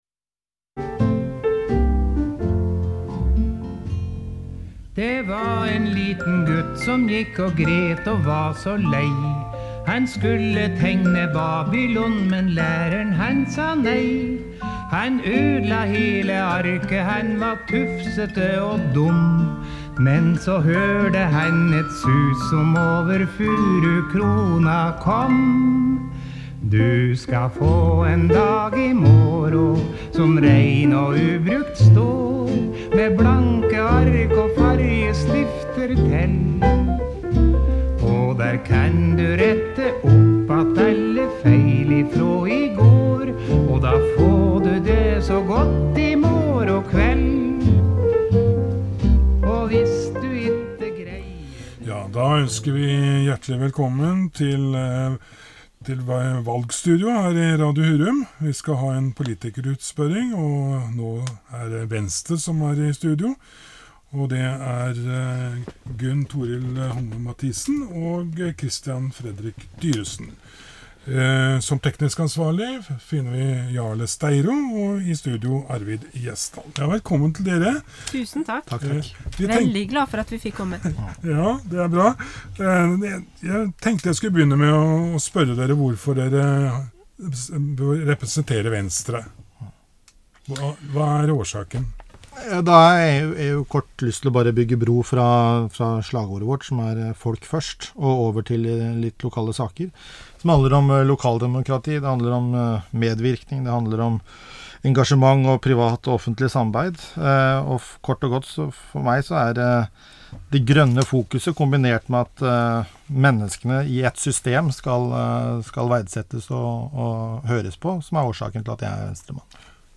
Lytt til radiointervju med Venstretoppene
Radio Hurum inviterte de politiske partiene på en times radiointervju om lokale problemstillinger i anledning kommunevalget 2015.